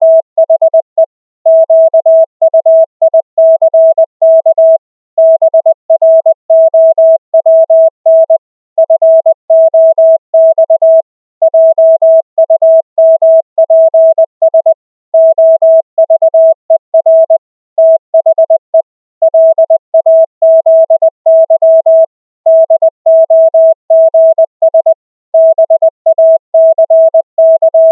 La velocità di trasmissione è 20 wpm (parole al minuto), corrispondenti a 100 caratteri al minuto.
• La prima cosa che osserviamo è che la trasmissione da parte del PC è la più breve.
REFERENCE-650-Hz.mp3